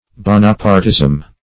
Bonapartism \Bo"na*part`ism\, n.